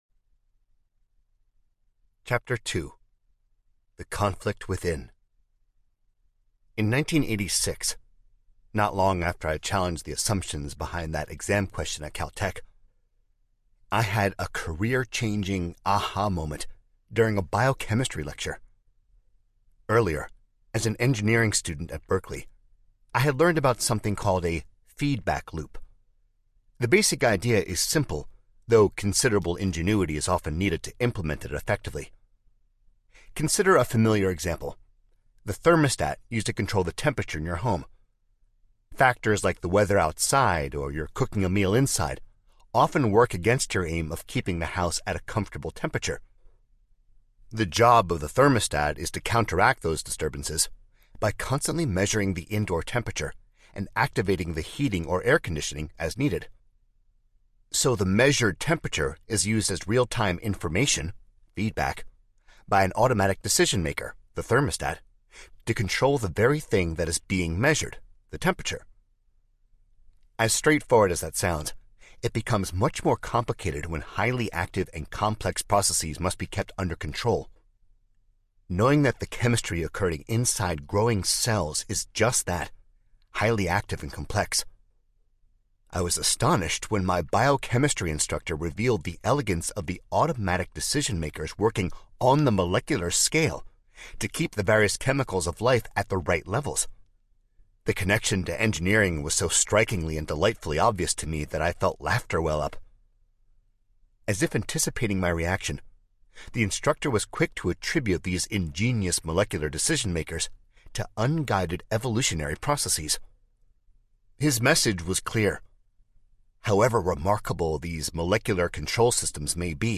Undeniable Audiobook
Narrator